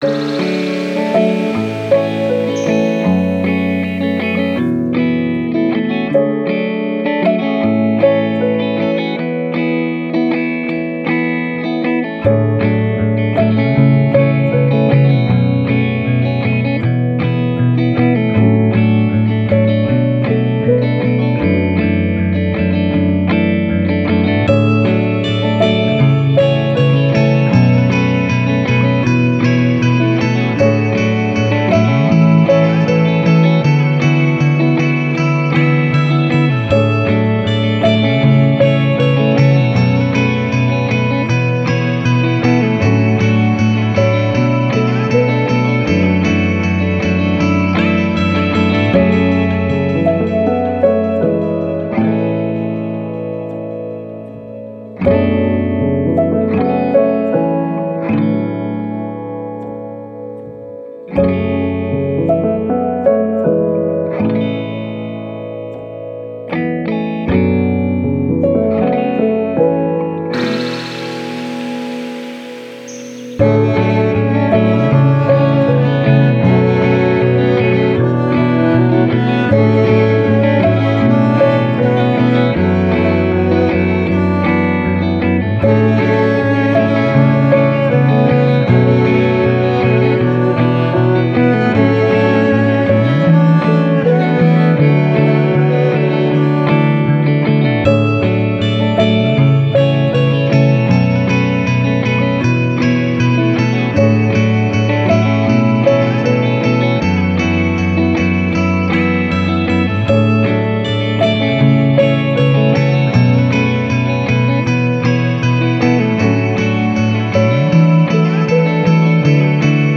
Downtempo, Soundtrack, Guitars, Story